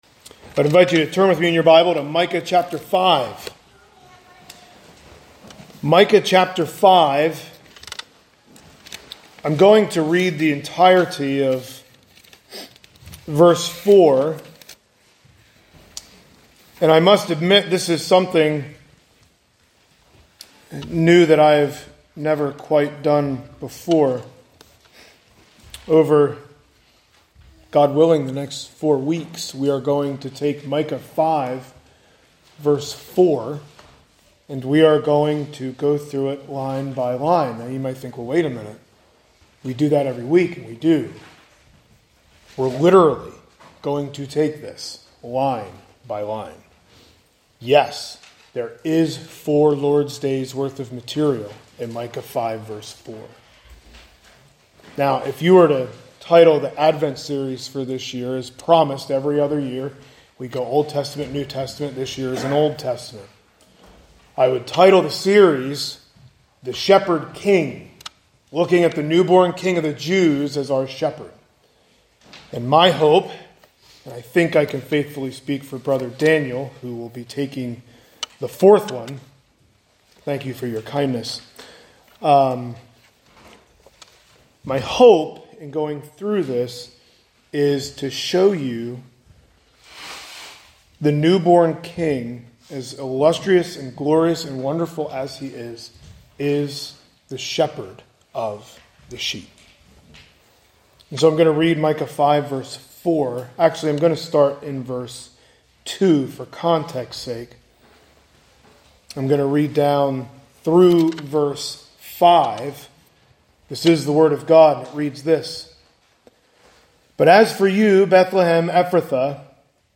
Sunday Morning Sermons | Zionsville Bible Fellowship Church